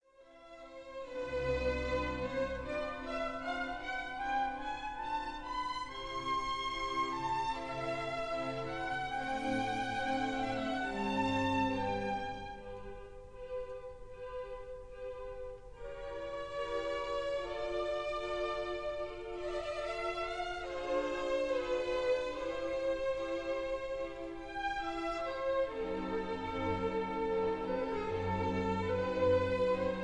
in C major